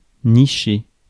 Ääntäminen
Ääntäminen France: IPA: [ni.ʃe] Haettu sana löytyi näillä lähdekielillä: ranska Käännös Konteksti Ääninäyte Verbit 1. nest US 2. nestle US 3. hang out slangi Määritelmät Verbit Faire son nid .